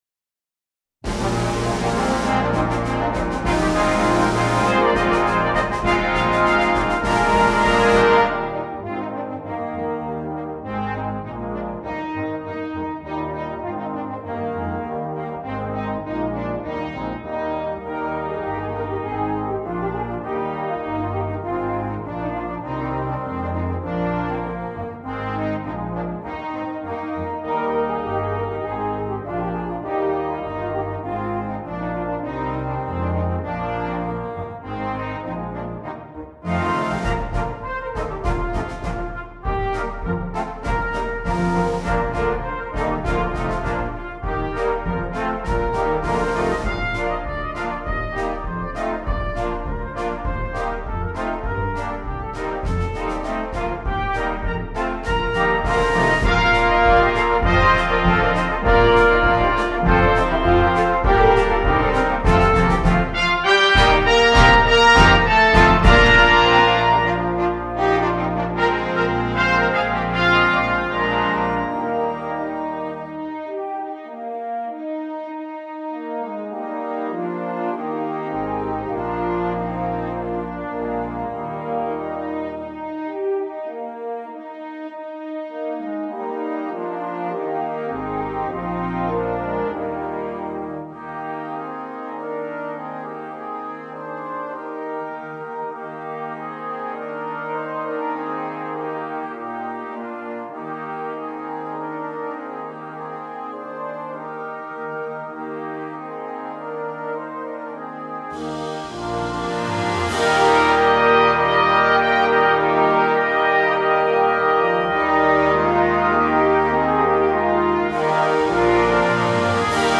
Full Band
without solo instrument
Christmas Music
– Eb Soprano Cornet
– the 3 Trombones